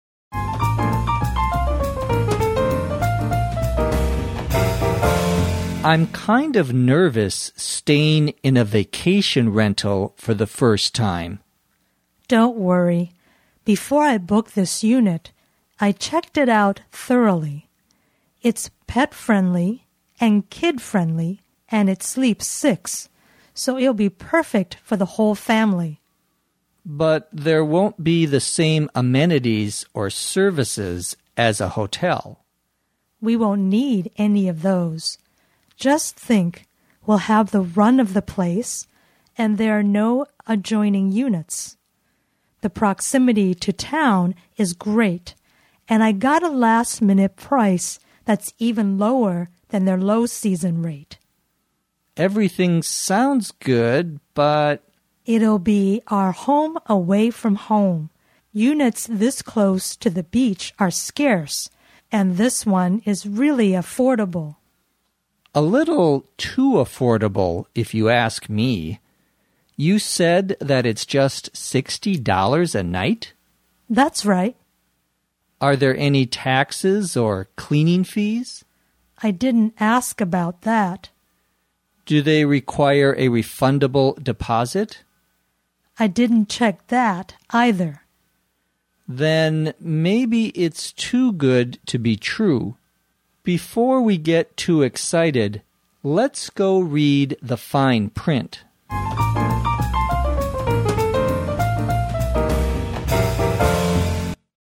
地道美语听力练习:租度假屋